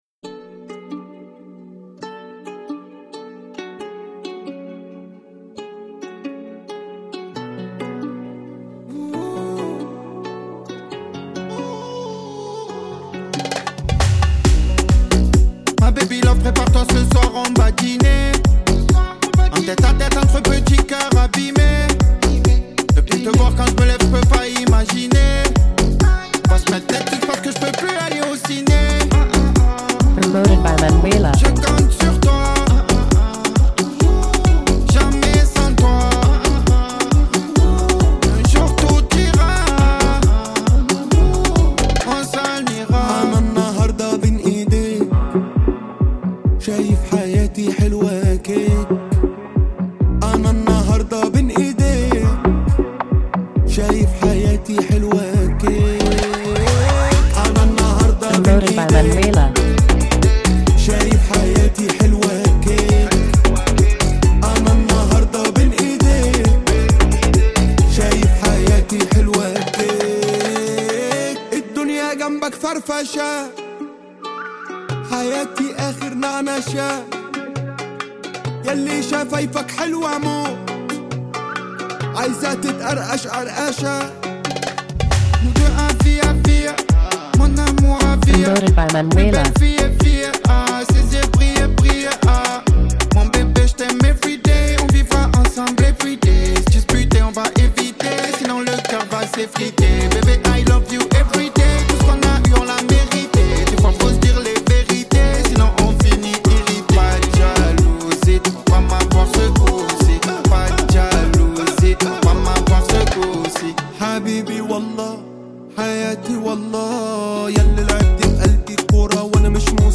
est un hymne survolté
production audacieuse de la pop égyptienne moderne
Radio Edit